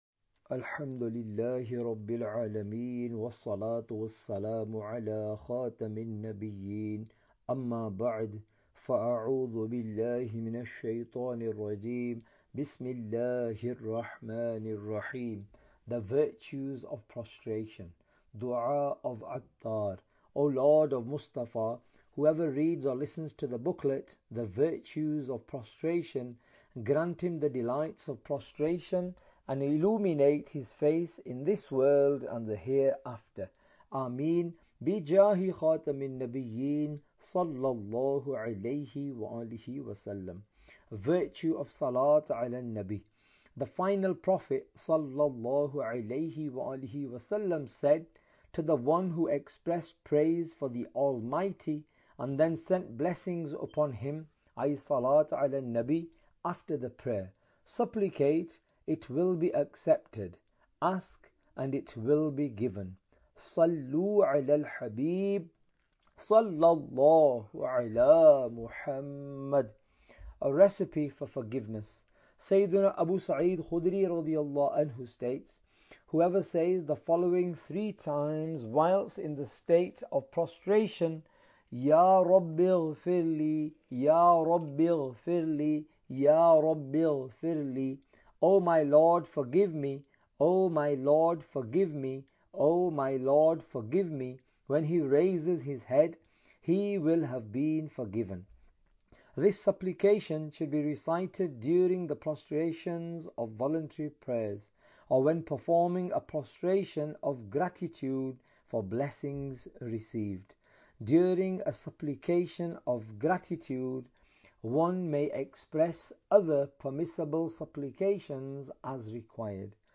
Audiobook – The Virtues of Prostration (English)